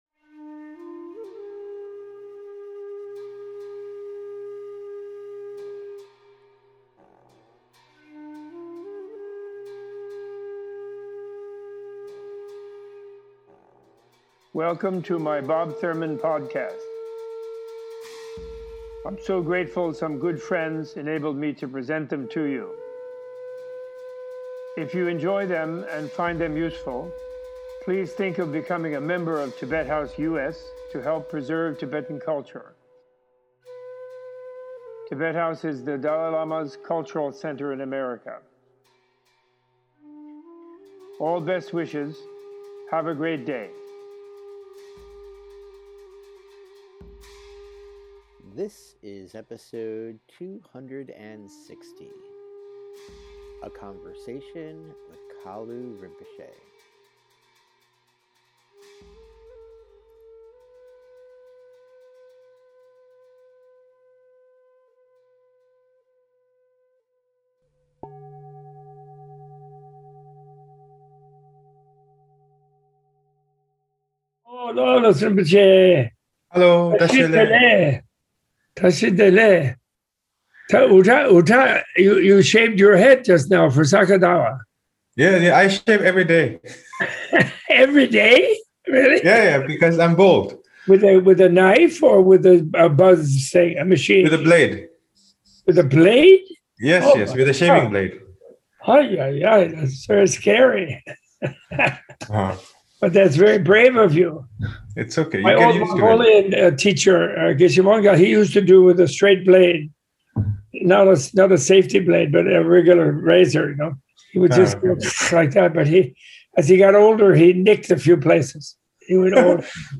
A Tibet House US | Menla Conversation with Kalu Rinpoche & Bob Thurman - Ep. 260
A-Tibet-House-US-Menla-Online-Conversation-with-Kalu-Rinpoche-Part-One.mp3